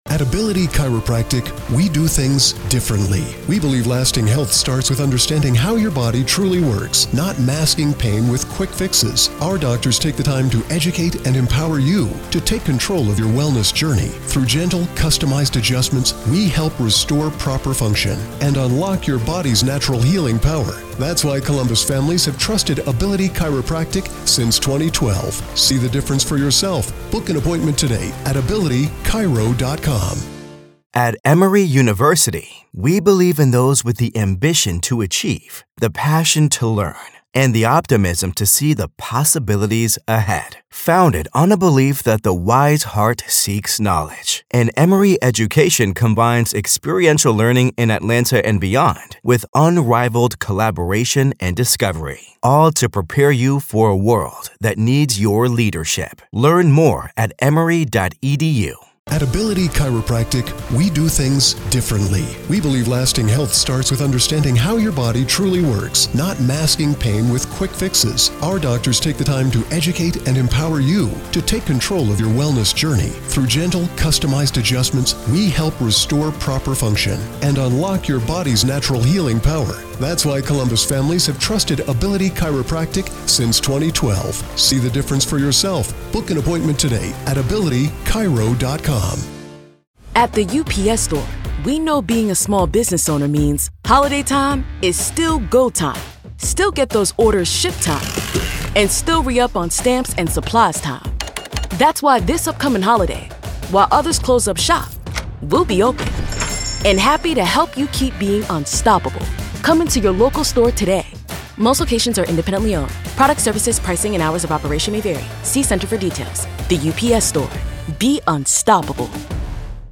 Welcome to a special episode of "The Trial of Karen Read," where today, we find ourselves inside the courtroom for a hearing in the case against Karen Read.